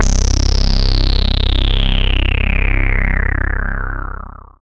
BAS_House hh11.wav